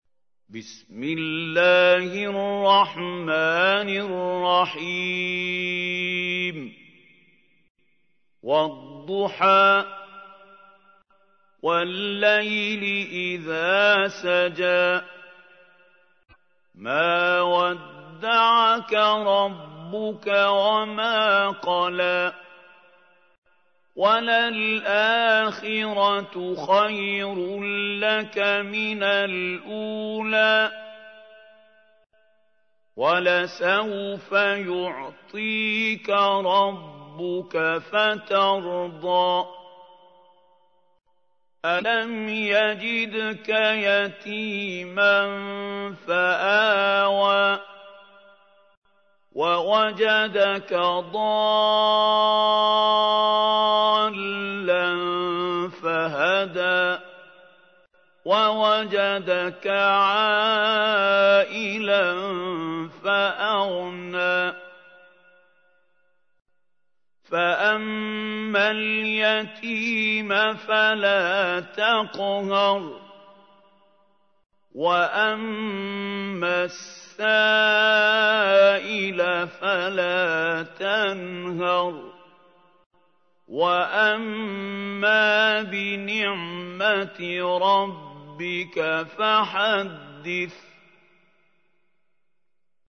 تحميل : 93. سورة الضحى / القارئ محمود خليل الحصري / القرآن الكريم / موقع يا حسين